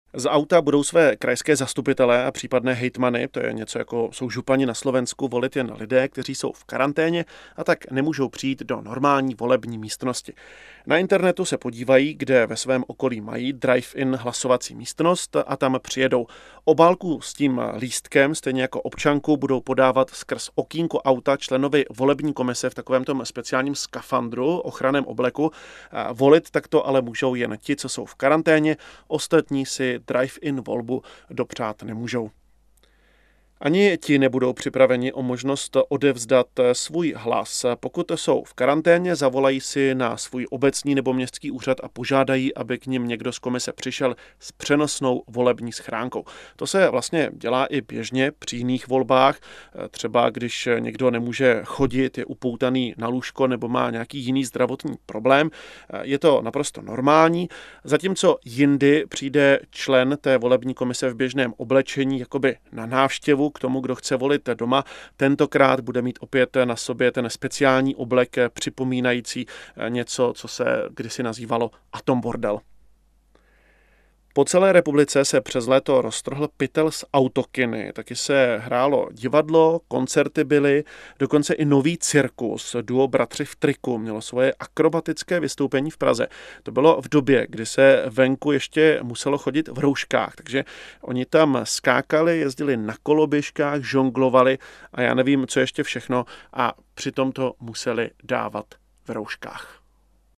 zpravodaj z CR.mp3